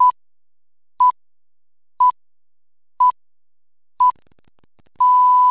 Real Tone: Ringtone
pips_real.mp3